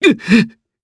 Clause-Vox_Sad_4_jp.wav